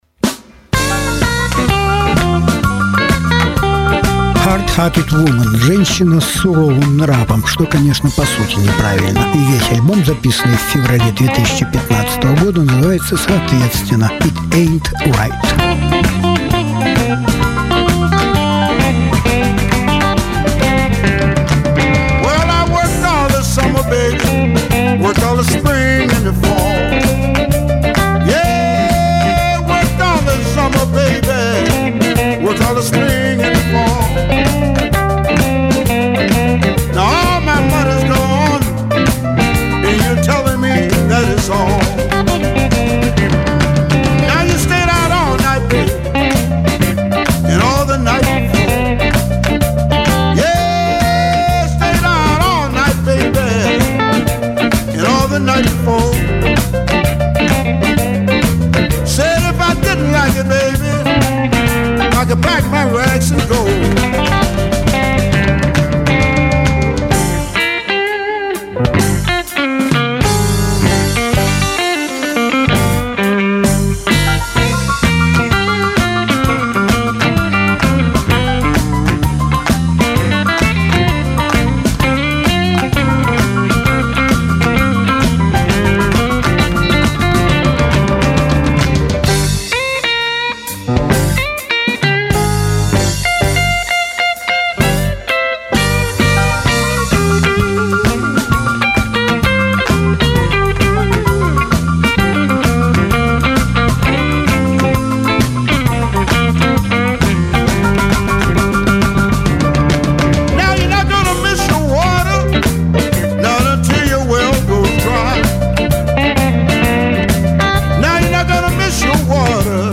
разные альбомы Жанр: Блюз СОДЕРЖАНИЕ 08.07.2019 1.
прошел 14-й Архангельский блюзовый фестиваль "Блюз в Архангельске" . 6. фрагмент интервью с гитаристом